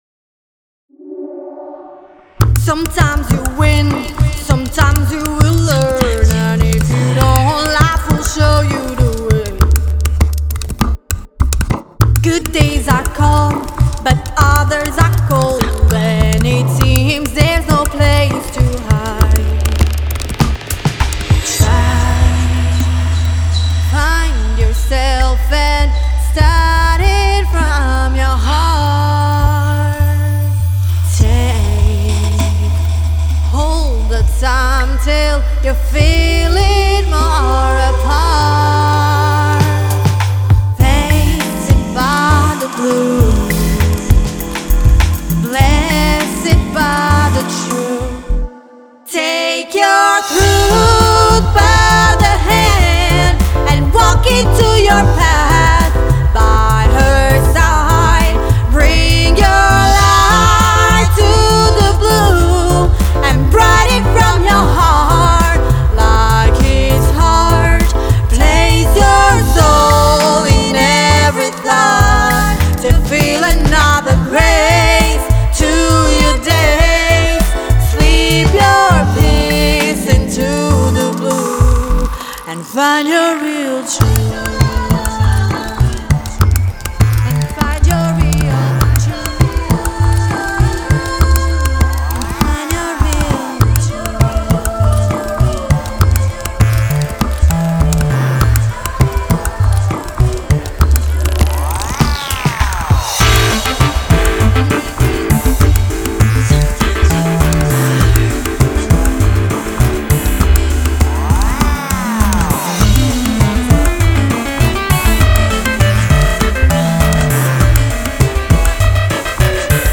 vocals
instrumental